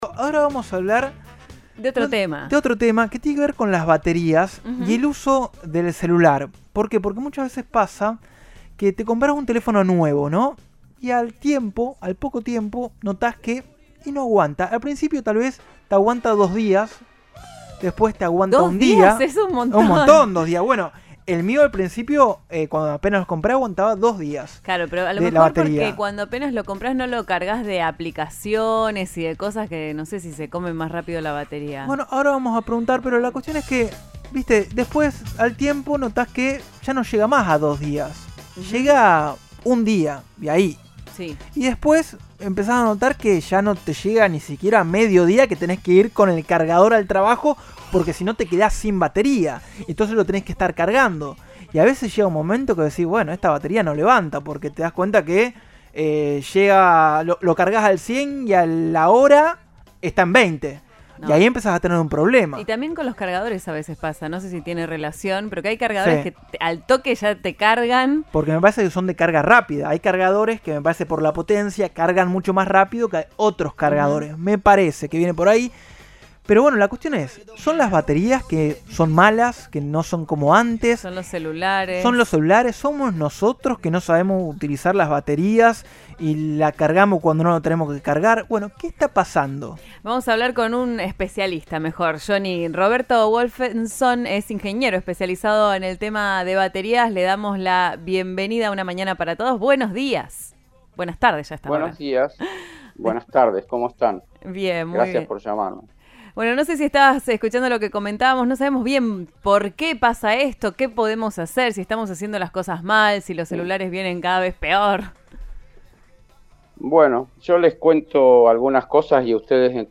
Un ingeniero especializado en esta tecnología habló en Cadena 3 Rosario sobre las características de la composición de los móviles que son parte de nosotros y los errores comunes en su uso.